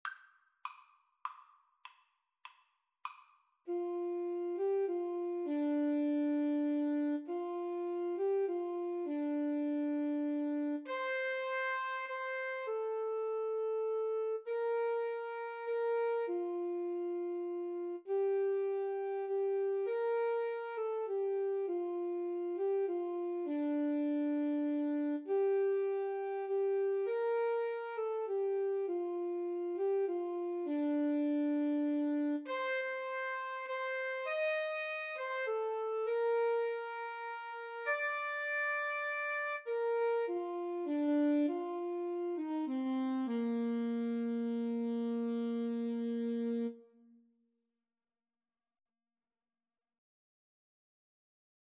Alto SaxophoneTenor Saxophone
6/8 (View more 6/8 Music)